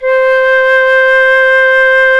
Index of /90_sSampleCDs/Roland L-CDX-03 Disk 1/CMB_Wind Sects 1/CMB_Wind Sect 6